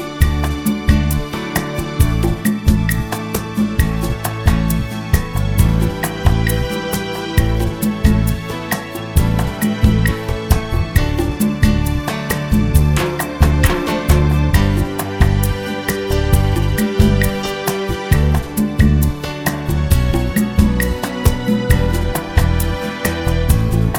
One Semitone Down Jazz / Swing 3:37 Buy £1.50